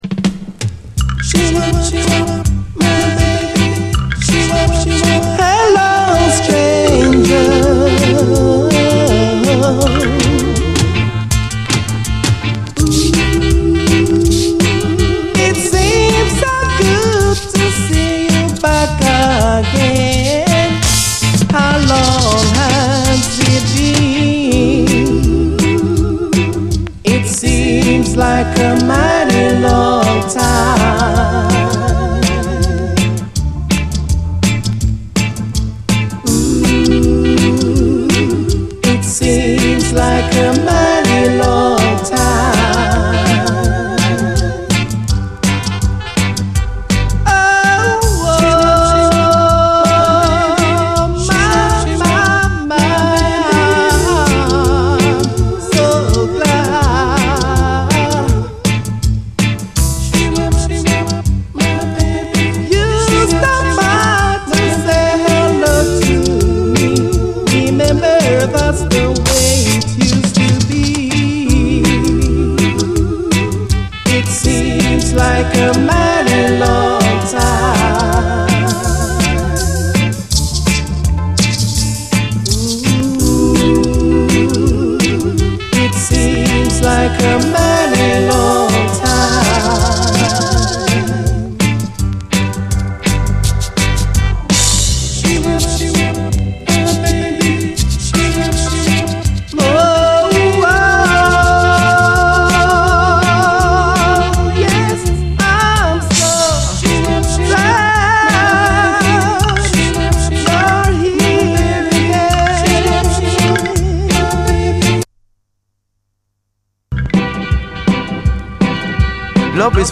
ヘタウマ系のイナタい歌声とイナタいリズムが味わい深い！
ズブズブの哀愁ルーツ・レゲエ